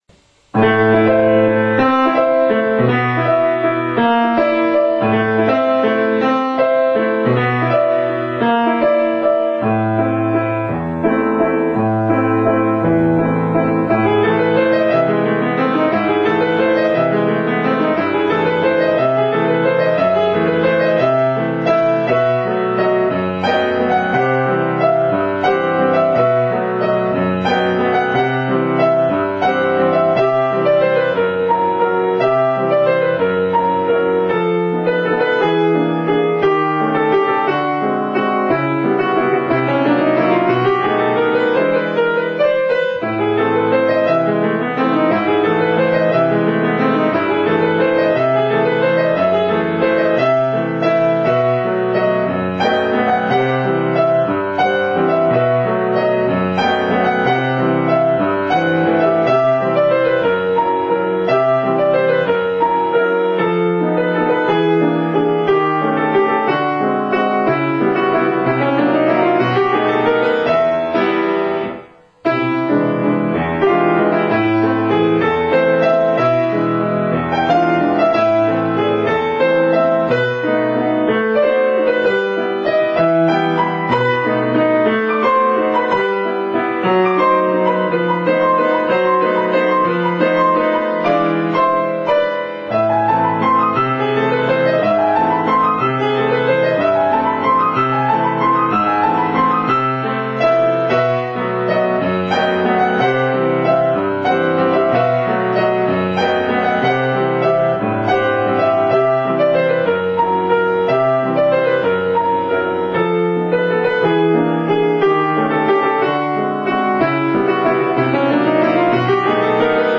ピアノのしらべ：ハチャトゥリアン作曲「仮面舞踏会」
「ワルツ」自体はとても華やかで、スケールの大きな音楽なのですが、どことなく怪しく妖艶な雰囲気が感じられます。